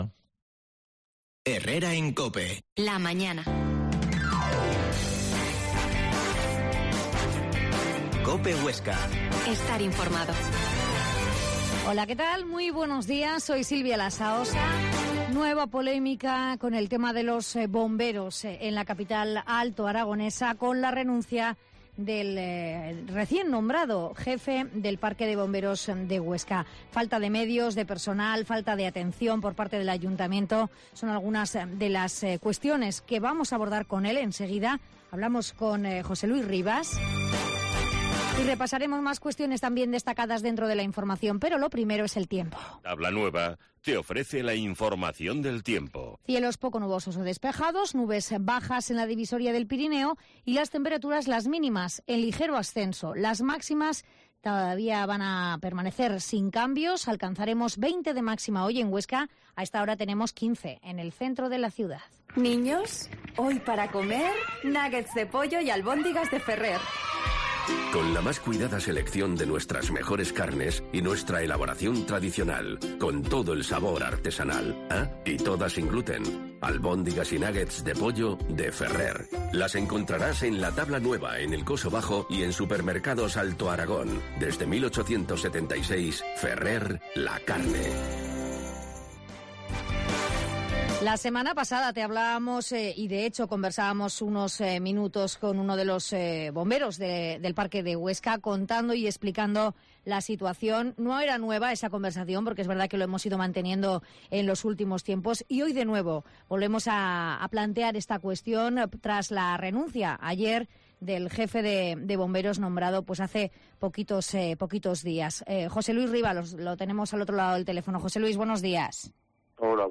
AUDIO: Herrera en Cope Huesca 12,50h. Entrevista